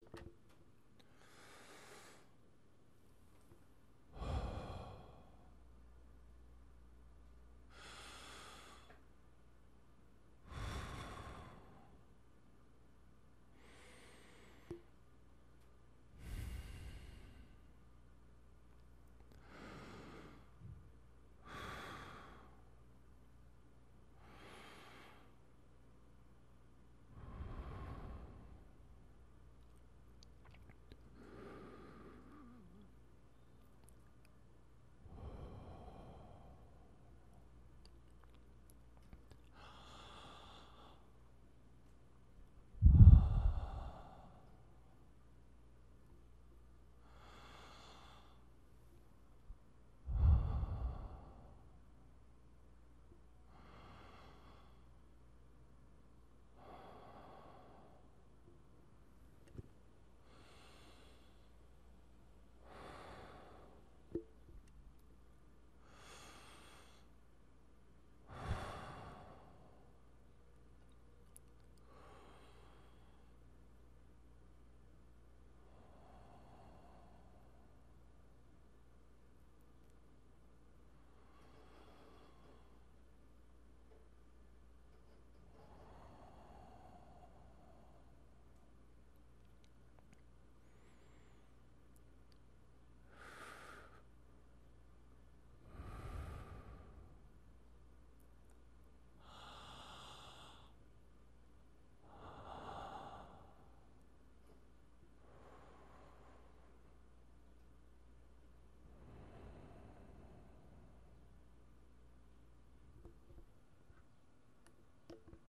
slow breath relax
Category 🎵 Relaxation
breath deep male relax sound effect free sound royalty free Relaxation